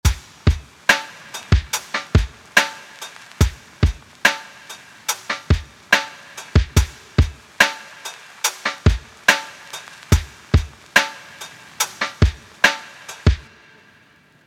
Swing auf den Hi-Hats
Die Hi-Hats müssen immer etwas Swing haben. Das bedeutet, dass sie nicht jede Achtel- oder 16tel-Note perfekt im Raster spielen, sondern immer etwas verschoben sind.
drums_zusammen.mp3